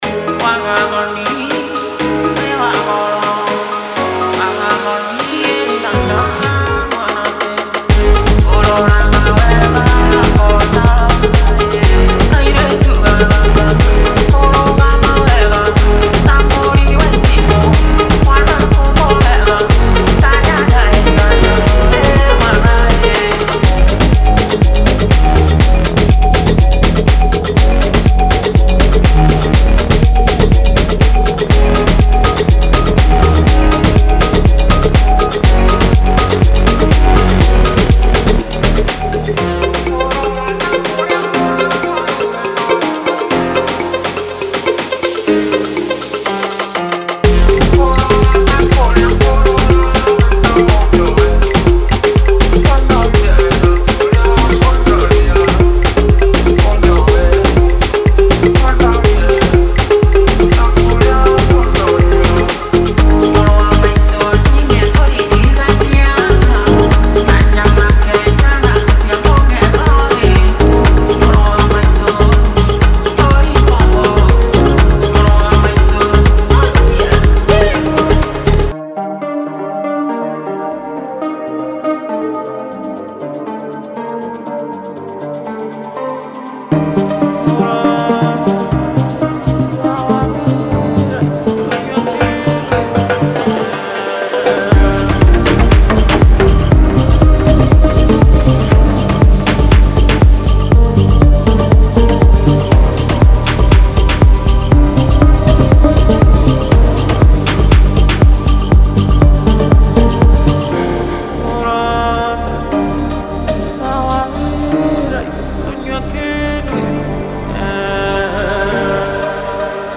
Melodic Techno Tribal
- 8 Construction Kits (from 120 to 122 BPM) 260 WAVS
- 120 Percussion Loops & Shakers
- 15 Pianos